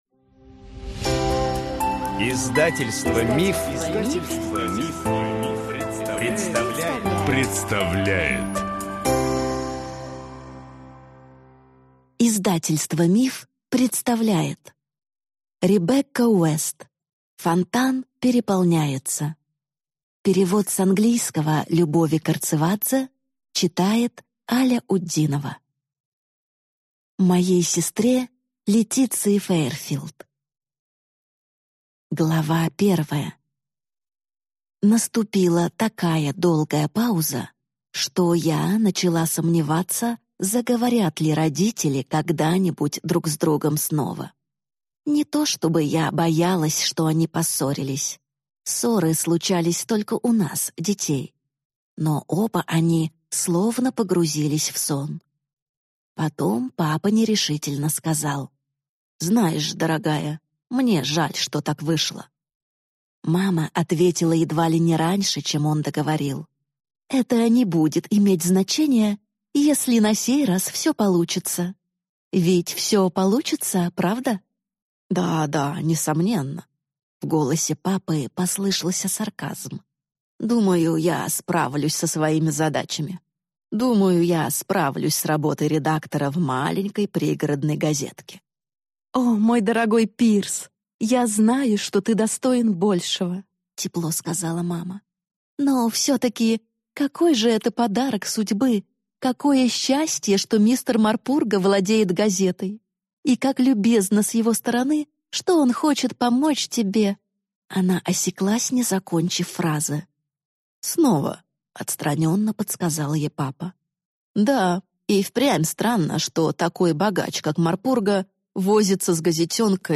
Аудиокнига Фонтан переполняется | Библиотека аудиокниг